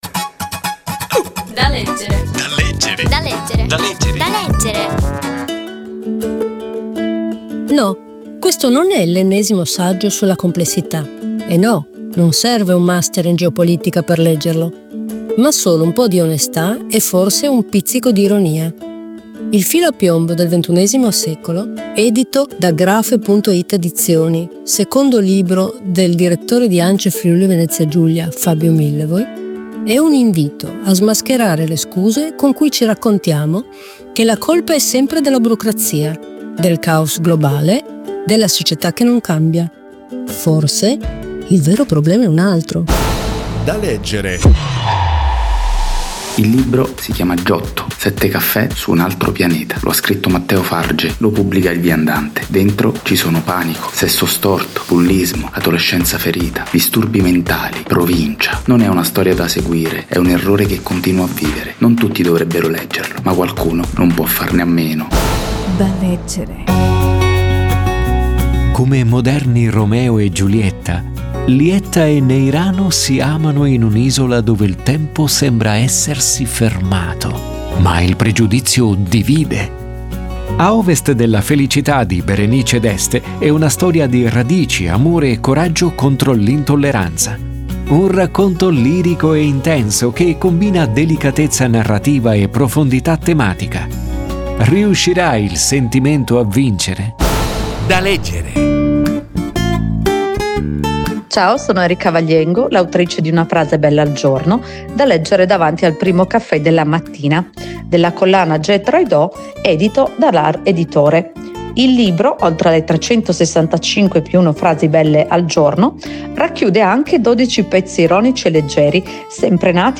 Recensione di: Il filo a piombo del XXI secolo
Tra i libri consigliati dal programma radiofonico DaLeggere! c'è anche il saggio di Fabio Millevoi